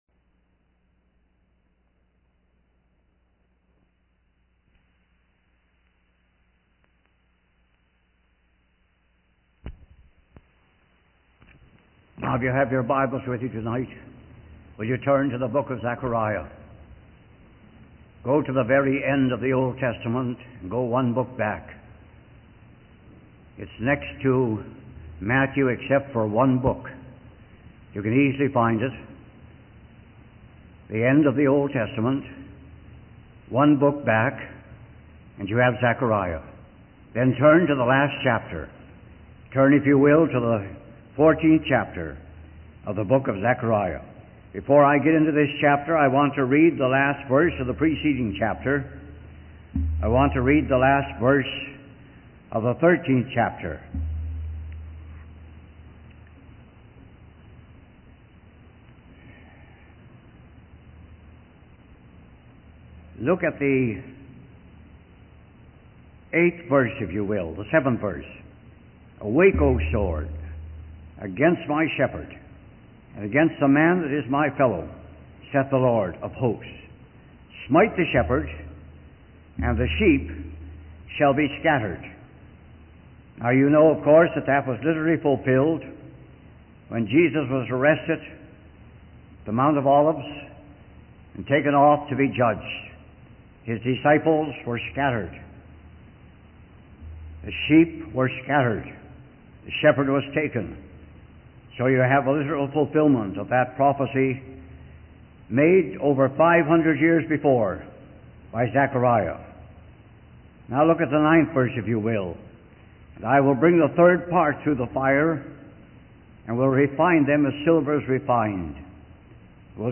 In this sermon, the preacher discusses the prophecy of Zachariah and its fulfillment in the future reign of Jesus Christ. The sermon emphasizes the importance of holiness unto the Lord during this future time.